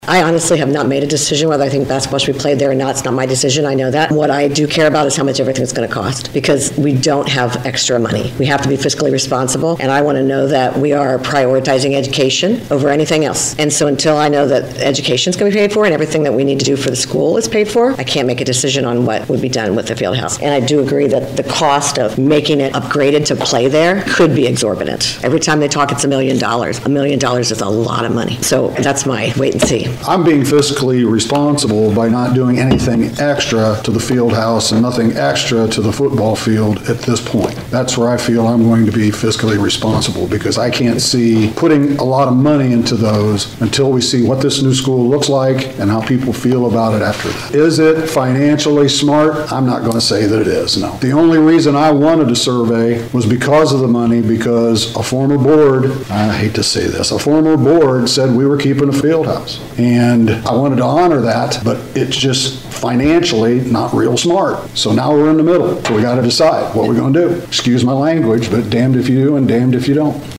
The Celina Board of Education held its March meeting on Monday March 17th. The board heard from community members expressing concern and seeking information on the future of the district's building project and the Celina Field House. During the meeting, board members Julie Sommer and Jon Clouse shared their thoughts: